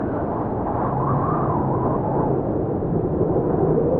Ghost Moan
Ghost Moan is a free horror sound effect available for download in MP3 format.
439_ghost_moan.mp3